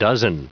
Prononciation du mot dozen en anglais (fichier audio)
Prononciation du mot : dozen